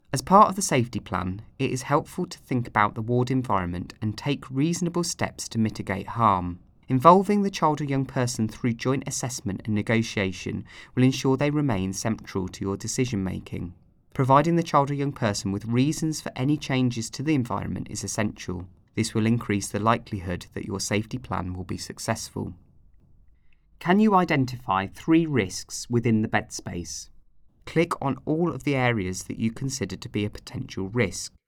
Narration audio (MP3) Narration audio (OGG) Contents Home Introduction What is Risk Assessment and Management?